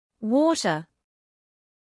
Australian: Diphthongs shift, /aɪ/ becomes /ɑe/ in “day.” High rising tone for questions.
Australian
water-Australian.mp3